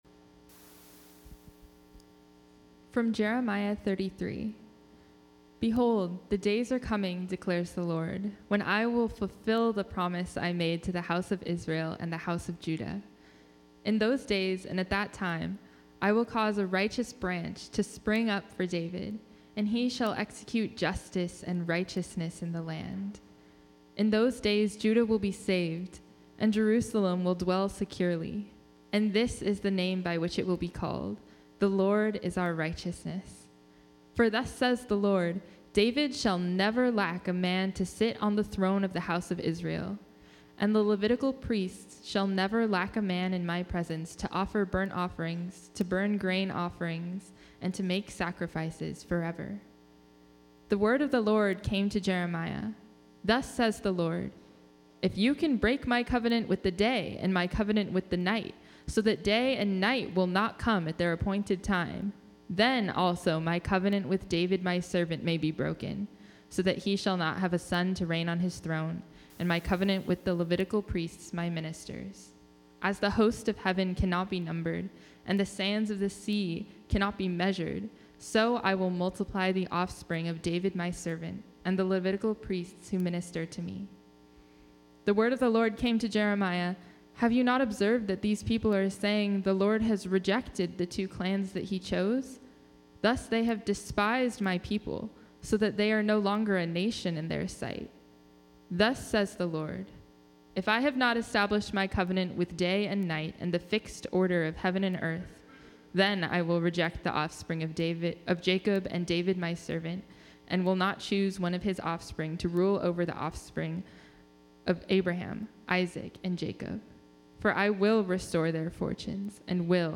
Online Service - Bridges Community Church Los Altos